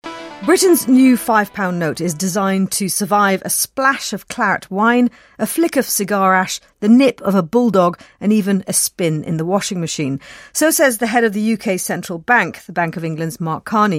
【英音模仿秀】塑料英镑 听力文件下载—在线英语听力室